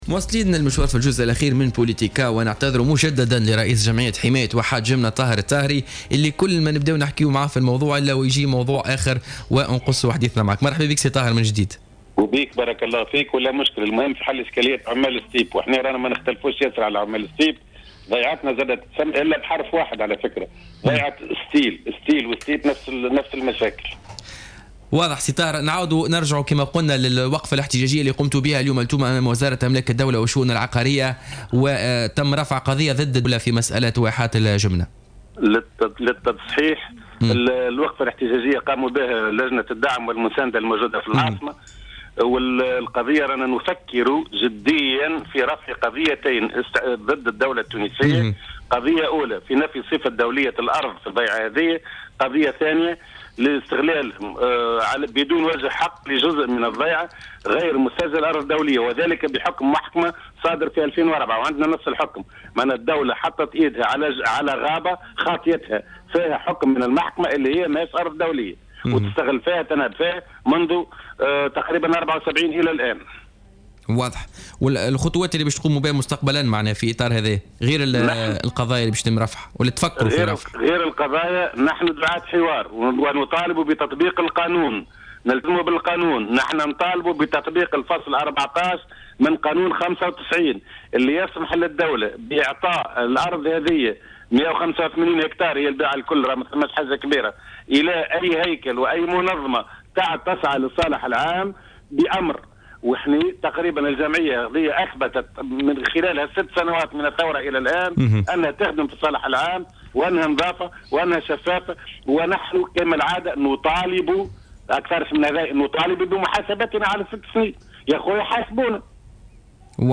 في تصريح للجوهرة أف أم في برنامج بوليتكا